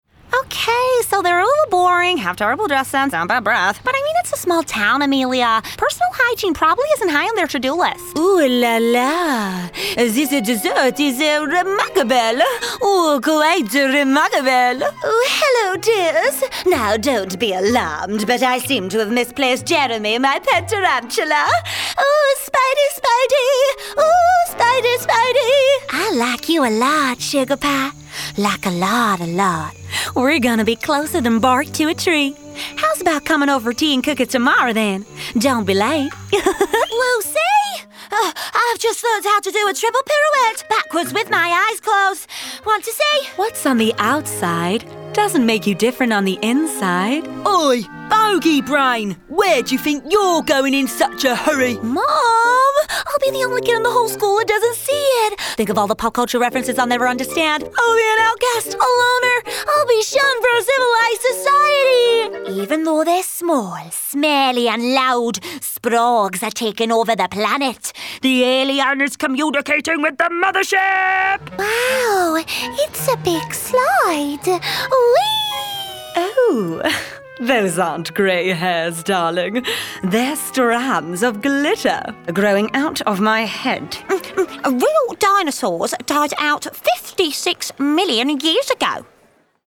Animation Showreel
Female
British RP
Neutral British
Bright
Friendly
Confident
Warm